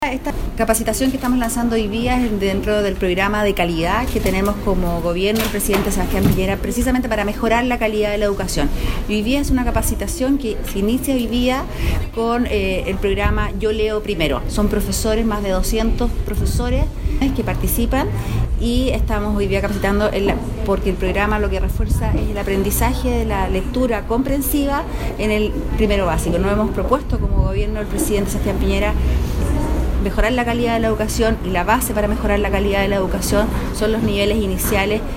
Claudia Trillo, Seremi de Educación, explicó que estas capacitaciones para los profesores forman parte de la integración de los programas de lectura, denominado “Yo leo primero” para los niños que cursan 1ero básico, cuyo objetivo es mejorar la calidad de la educación y el reforzamiento de la lectura compresiva.